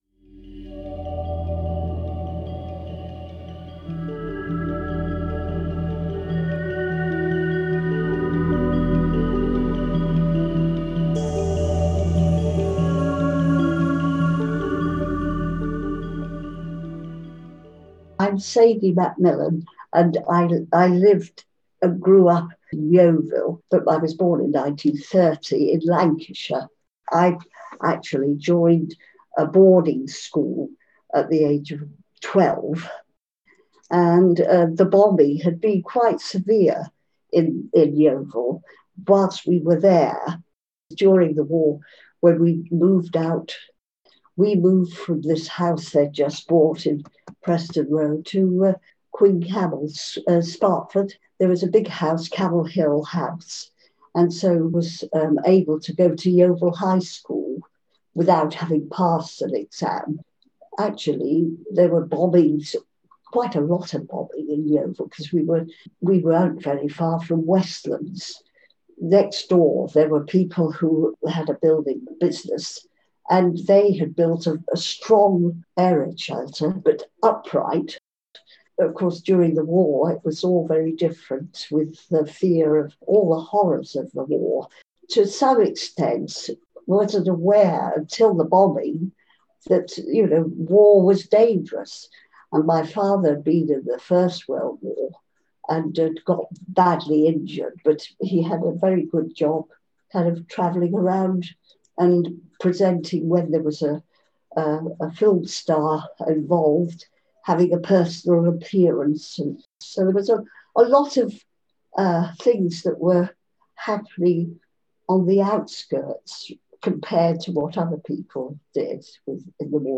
interviewed residents and staff from Somerset Care and local elders from Yeovil